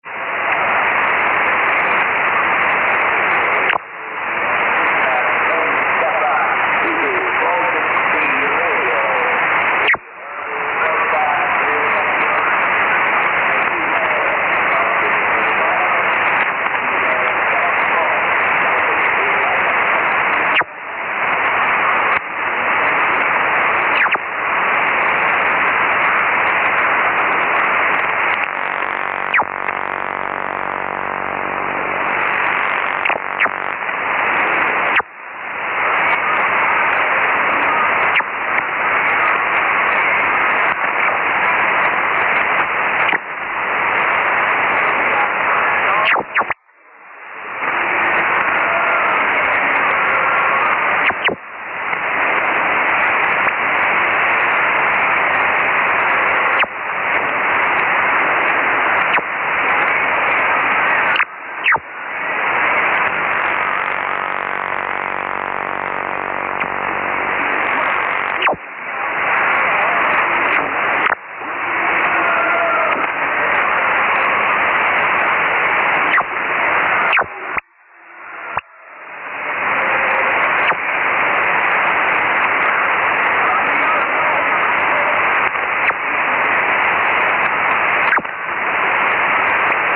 Baltic Sea Radio was received in Japan today by five listeners!!
Frequency 14.350 MHz USB at 08.00 UTC >>